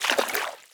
Footstep_Water_07.wav